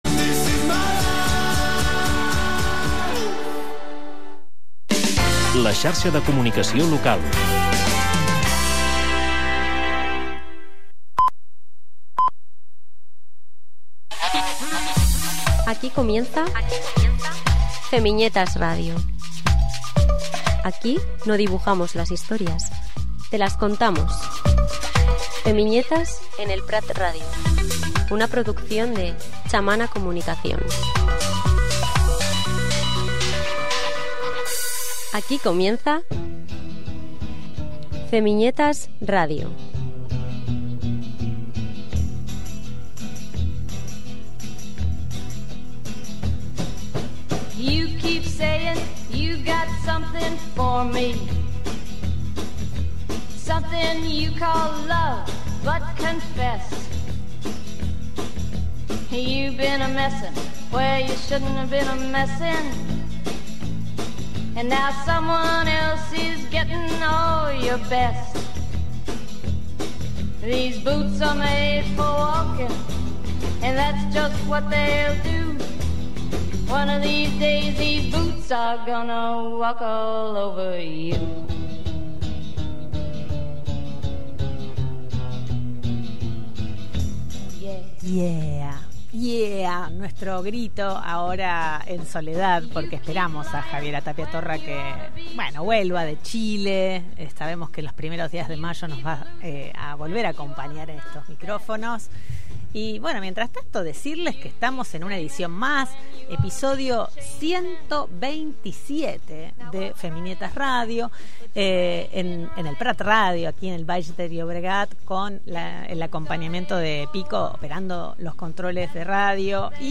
Entrevistes i debats en el món feminista d'actualitat, la política i l'art. Una xerrada amb il·lustradores, escriptores, poetesses, dones rebels, boges, grosses, lletges, somiadores, eloqüents, que parlen de com canviar el món a partir de la paraula i la imatge.